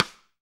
SD RI37.wav